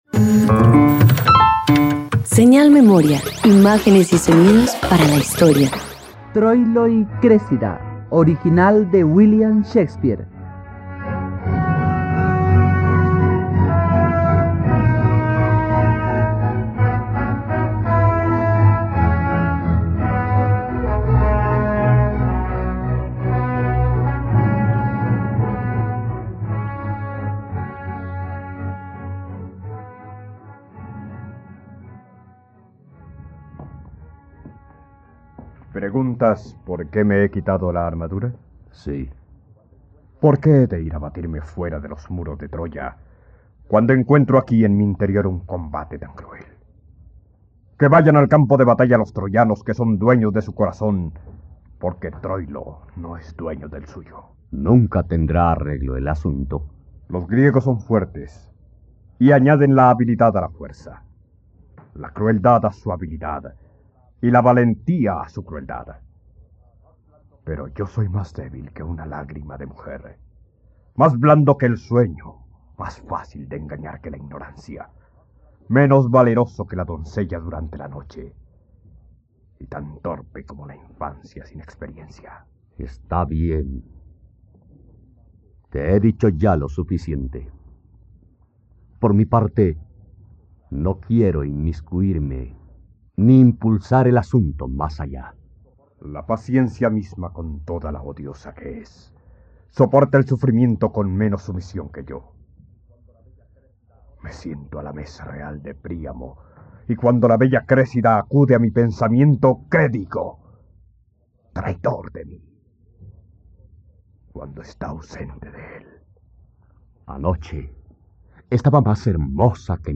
..Radionovela. Escucha ahora la adaptación radiofónica de Troilo y Crésida en los Radioteatros dominicales de la plataforma de streaming RTVCPlay.